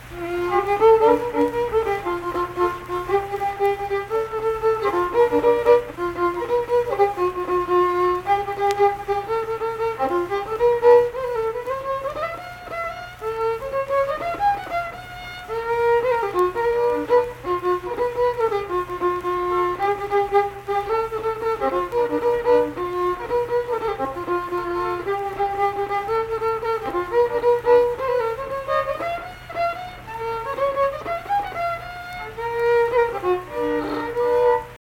Unaccompanied vocal and fiddle music
Instrumental Music
Fiddle
Pleasants County (W. Va.), Saint Marys (W. Va.)